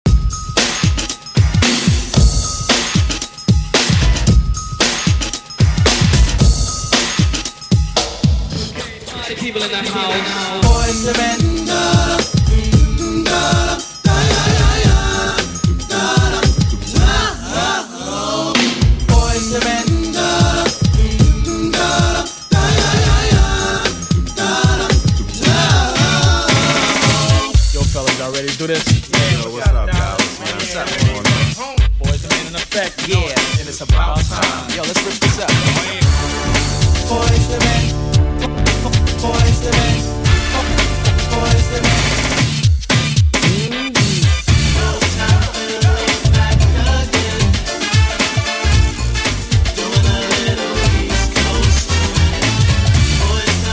fuse Top 40, Hip Hop, House, Dance, Country and Old School